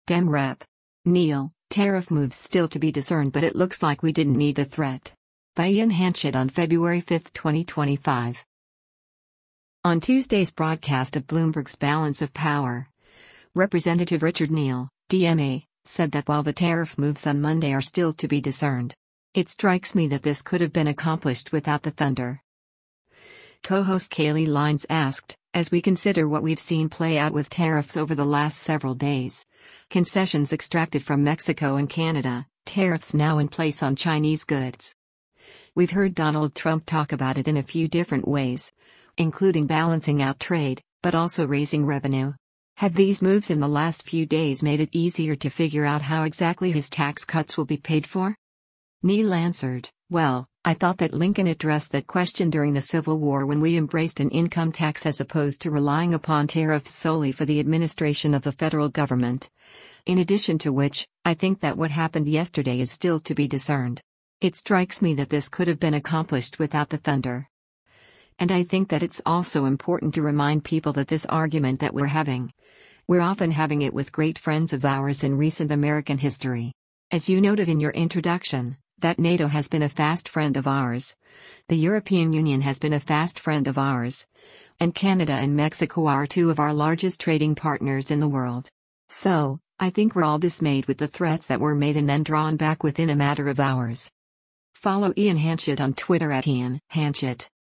On Tuesday’s broadcast of Bloomberg’s “Balance of Power,” Rep. Richard Neal (D-MA) said that while the tariff moves on Monday are “still to be discerned. It strikes me that this could have been accomplished without the thunder.”